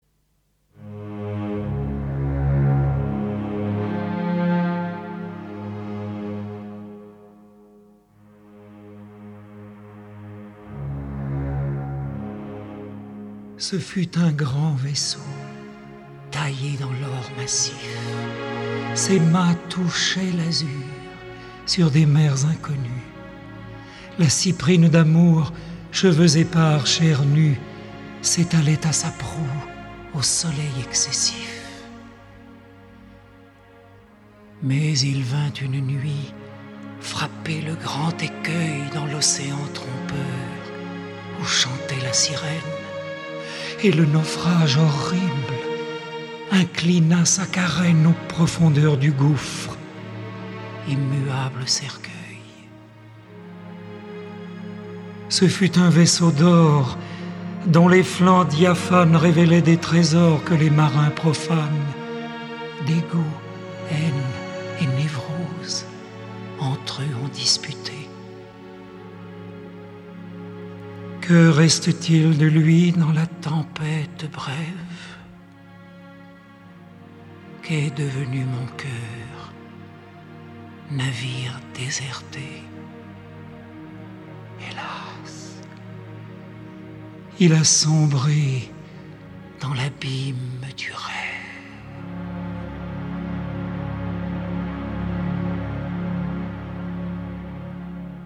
Lauréat du Grand Prix de l’Académie Charles Cros, en France, cet enregistrement vous touchera l’âme, à travers sa musique et sa poésie.